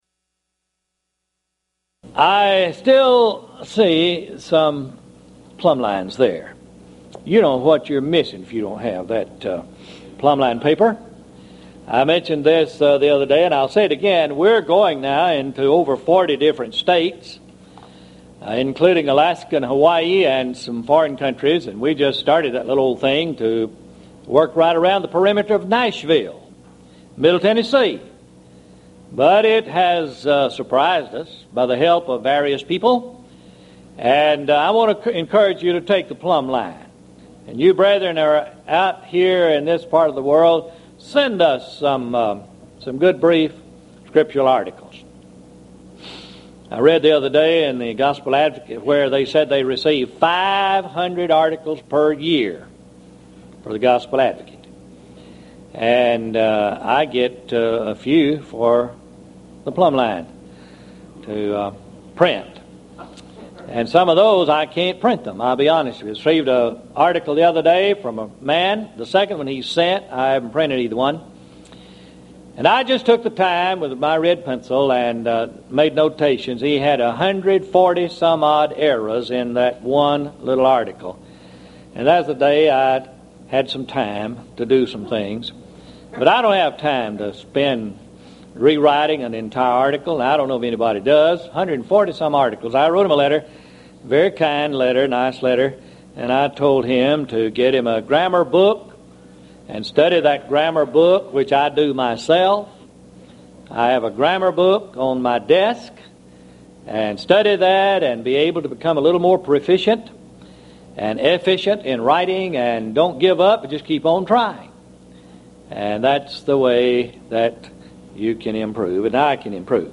Series: Houston College of the Bible Lectures Event: 1996 HCB Lectures